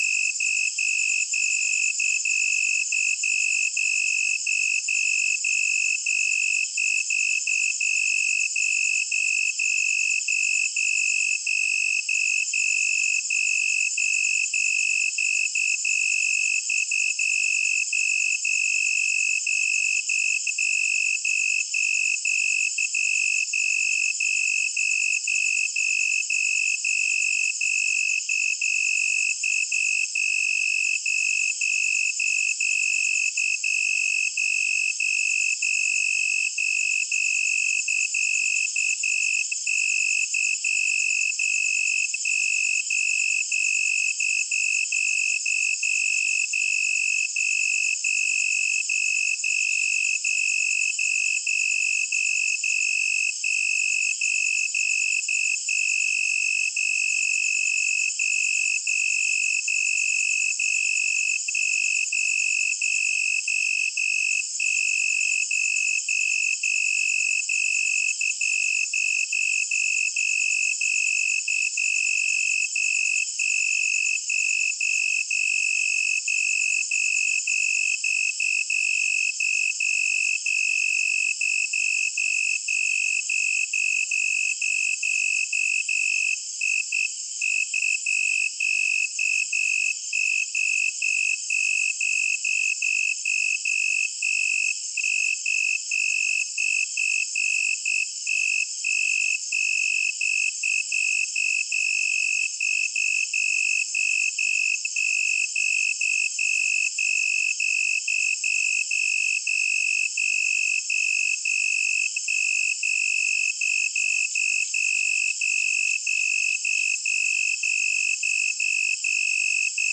🌲 / foundry13data Data modules soundfxlibrary Nature Loops Forest Night
forest-night-3.mp3